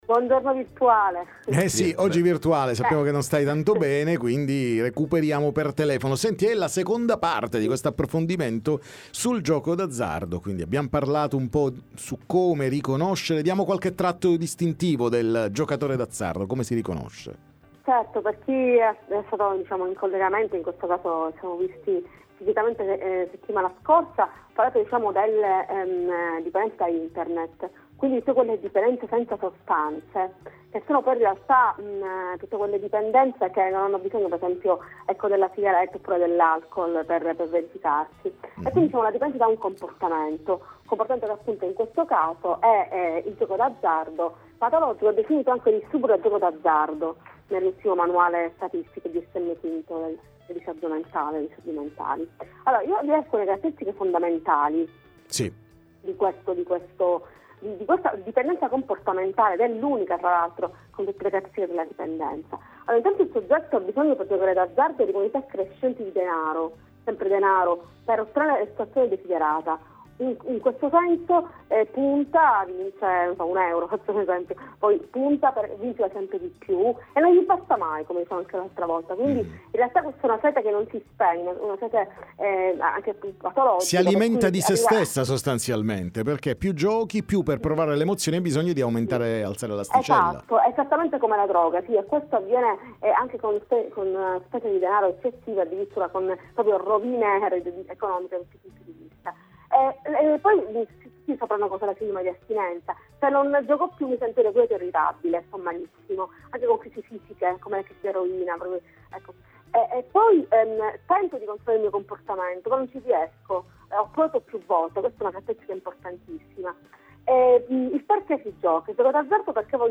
parliamo con lei nei nostri studi